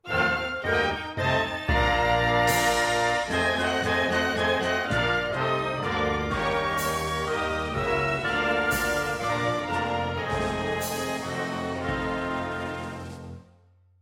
SC_RB_ritardando_76_orchestra_excerpt_thirteen_b_Bbmaj